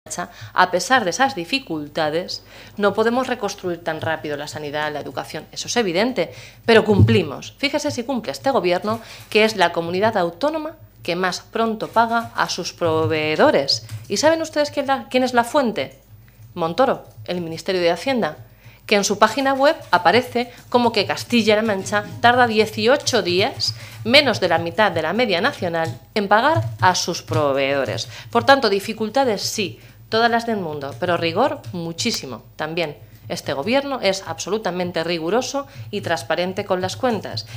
La portavoz del Grupo socialista en las Cortes de Castilla-La Mancha, Blanca Fernández, ha asegurado hoy que los dirigentes del PP en la región “hacen el ridículo” exigiendo que el gobierno de nuestra comunidad autónoma apruebe ya los presupuestos, “cuando no han sido aprobados en ninguna de las regiones donde ellos gobiernan”.
Cortes de audio de la rueda de prensa